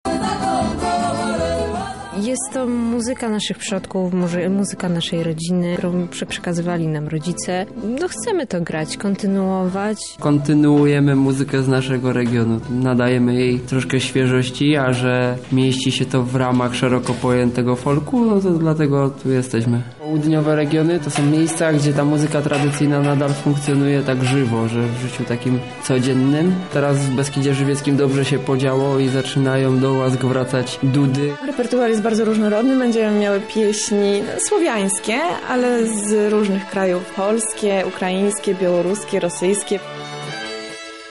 Skrzypce, dudy i bogato zdobione stroje.
Artyści prezentują się w ludowym repertuarze. Do tego etapu zakwalifikowało się 11 kapel i wokalistów z całej Polski.